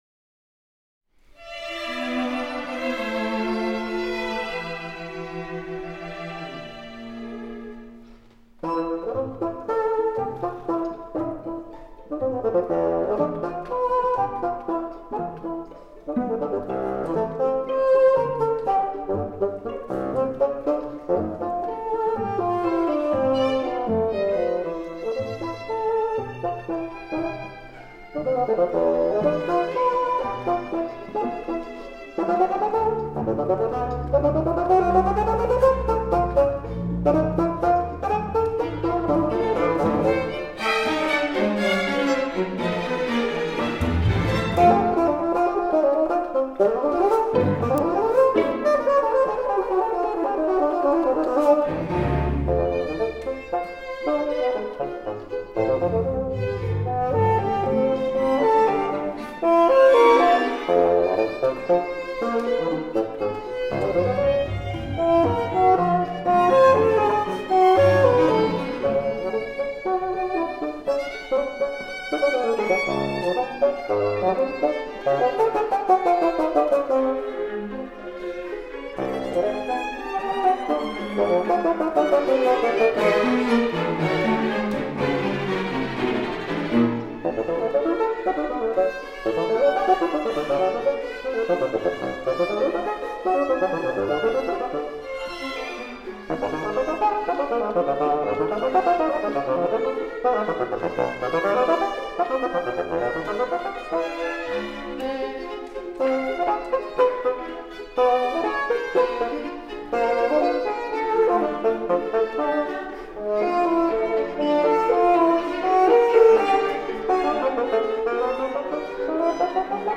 bassoon. Jean Françaix (1912): I Allegro moderato. From “Concerto for Bassoon and 11 String Instruments”.
Orchestre de la Suisse Romande.